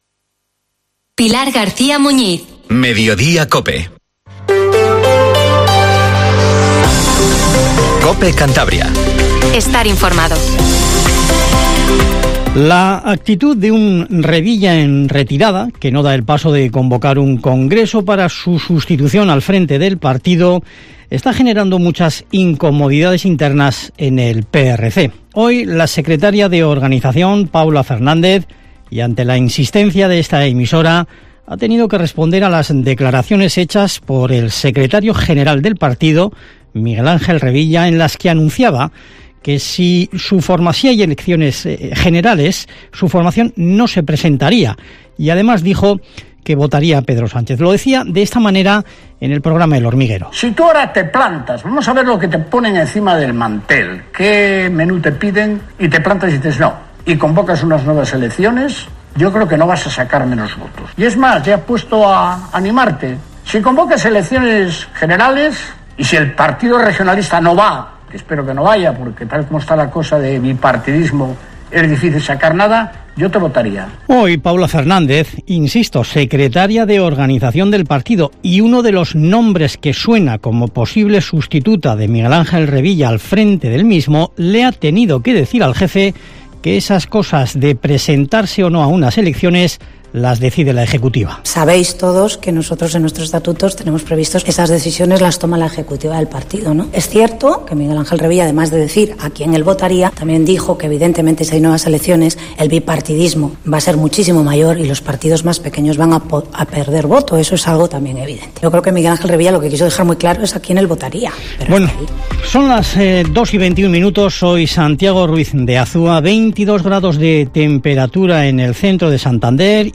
Informativo MEDIODIA en COPE CANTABRIA 14:20